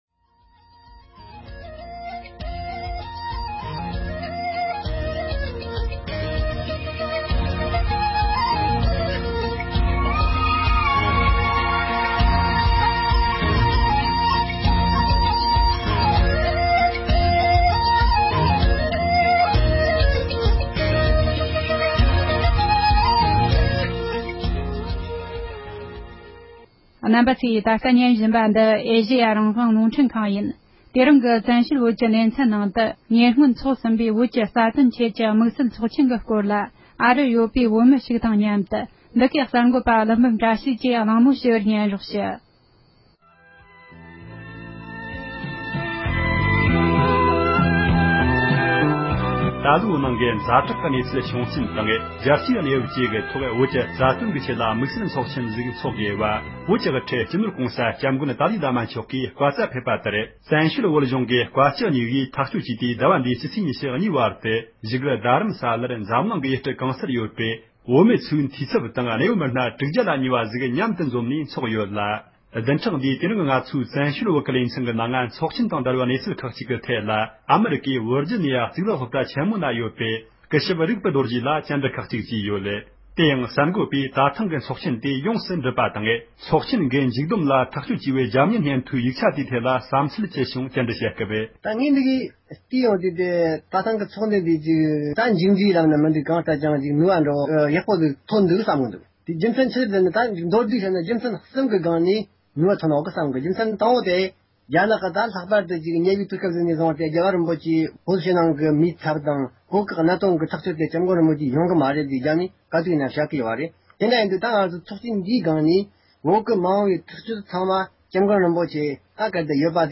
ཨ་རི་བར་ཇི་ནུཡ་ནས་ཡིན་པའི་བོད་མི་ཞིག་གིས་དམིགས་བསལ་ཚོགས་ཆེན་ཐོག་སྒེར་གྱི་བསམ་ཚལ་བཤད་ཡོད་པའི་གནས་ཚུལ།
སྒྲ་ལྡན་གསར་འགྱུར།